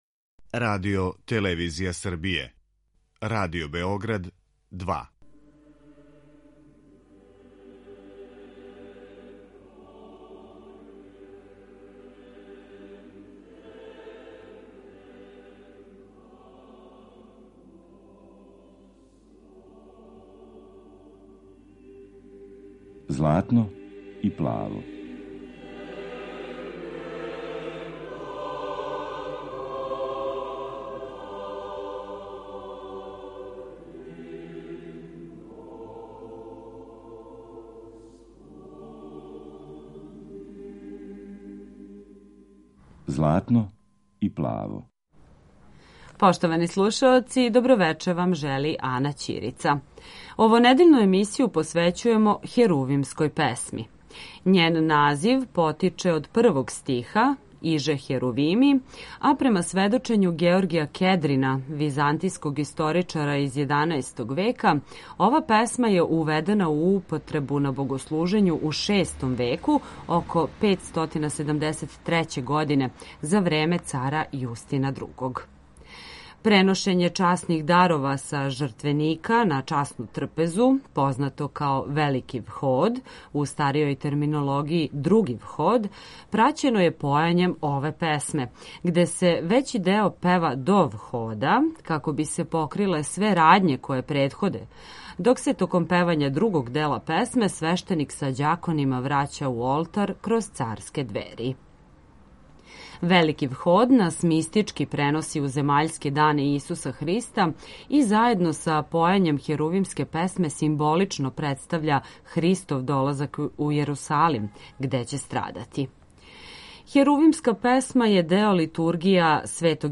Емисија посвећена православној духовној музици.